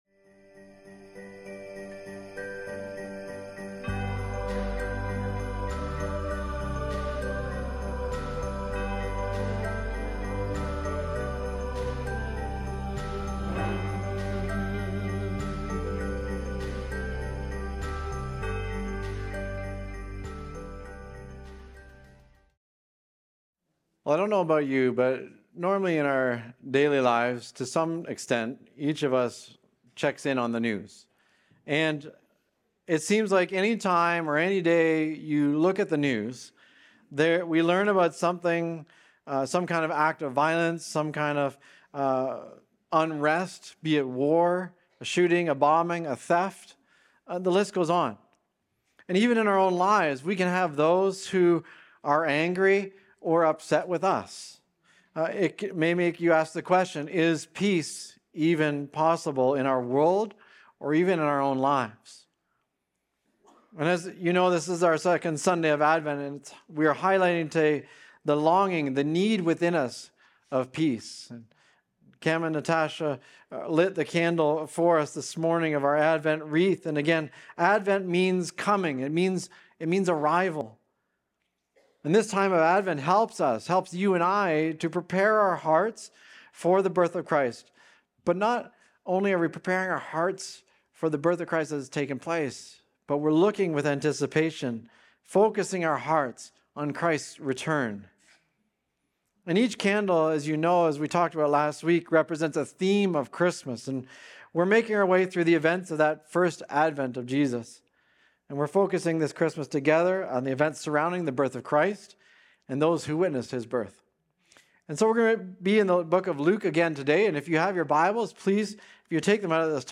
Recorded Sunday, December 7, 2025, at Trentside Fenelon Falls.